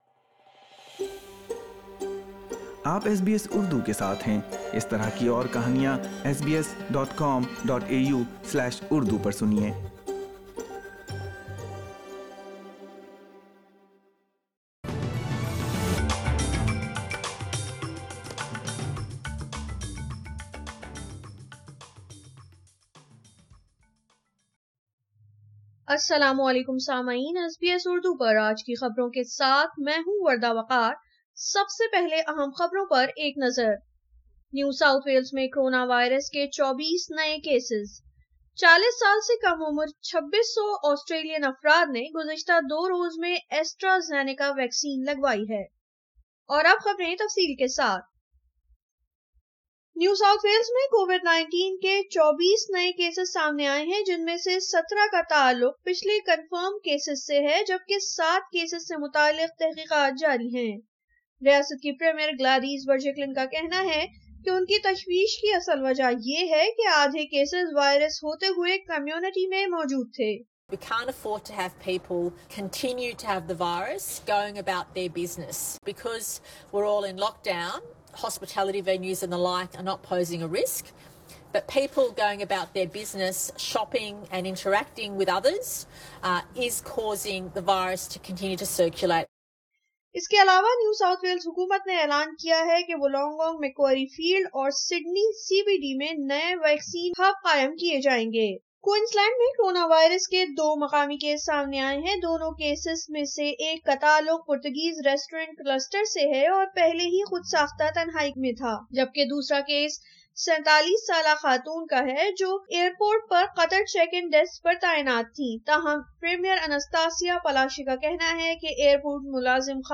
SBS Urdu News 01 July 2021